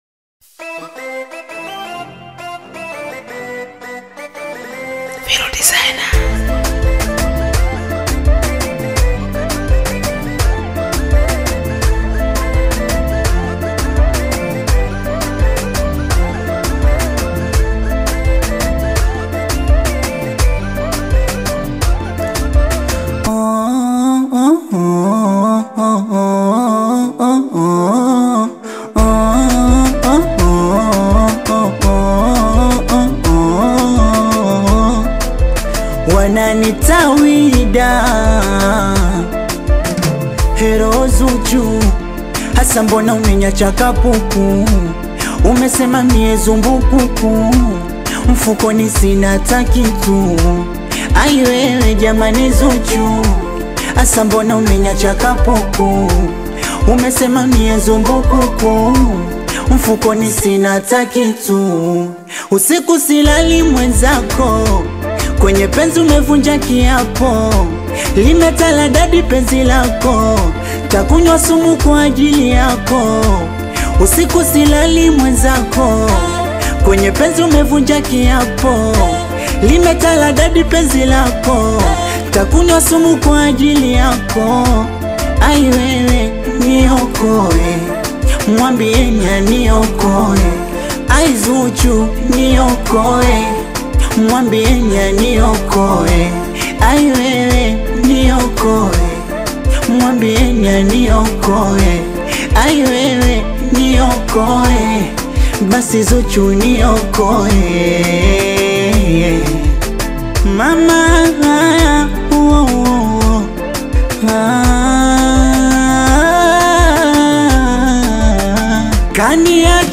love song
With sweet melodies and expressive vocals